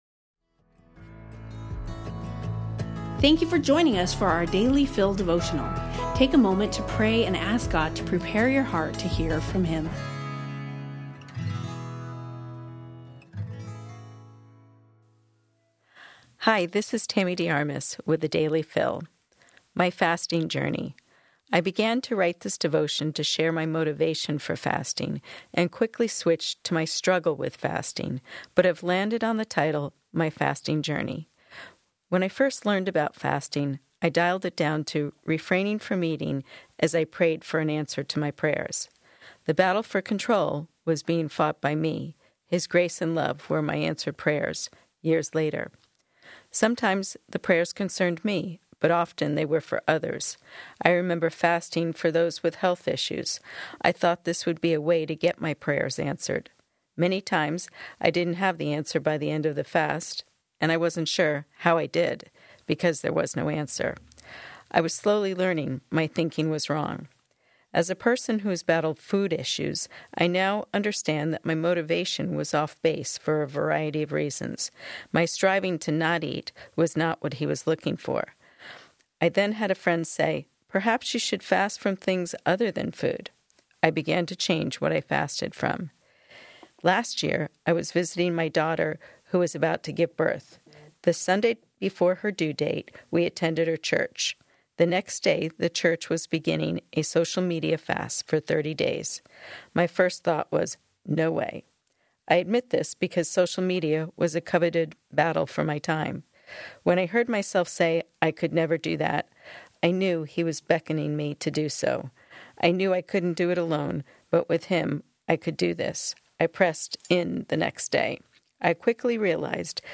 We hope you will enjoy these audio devotionals.